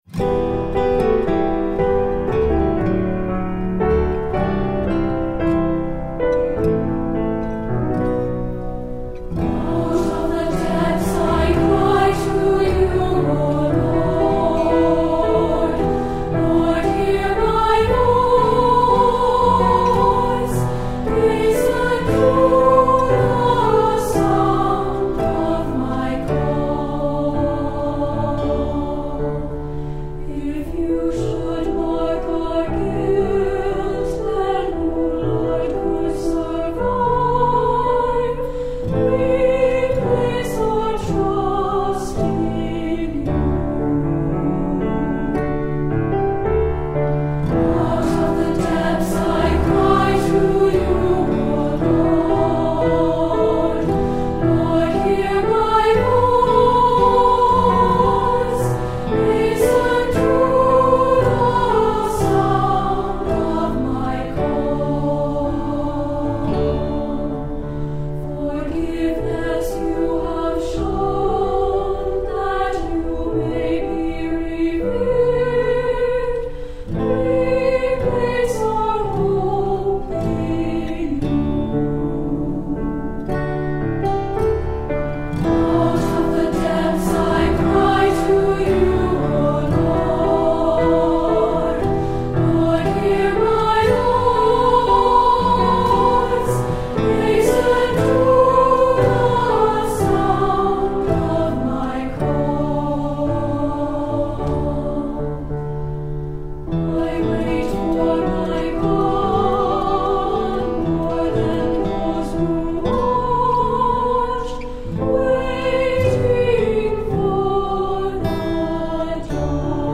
Anthems for Treble Voices
Unison with piano or guitar and optional congregation